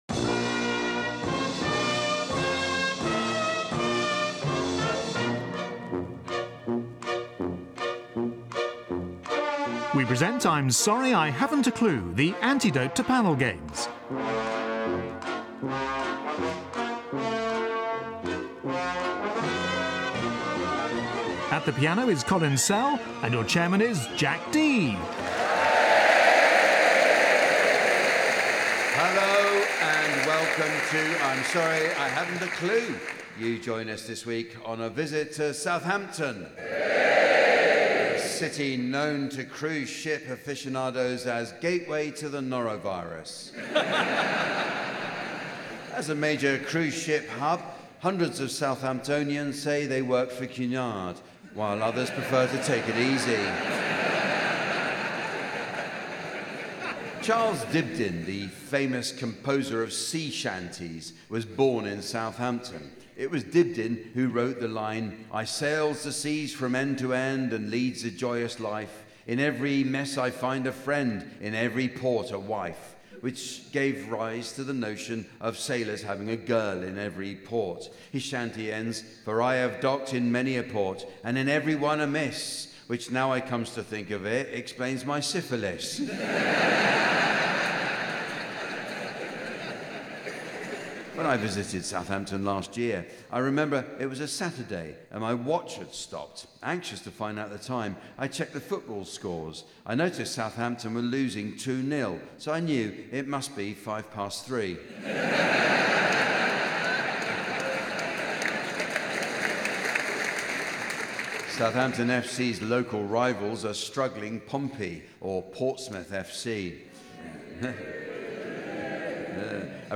The godfather of all panel shows returns to the Mayflower in Southampton. On the panel are Adrian Edmondson, Rachel Parris, Miles Jupp and Marcus Brigstocke, with Jack Dee in the umpire's chair....
Regular listeners will know to expect inspired nonsense, pointless revelry and Colin Sell at the piano.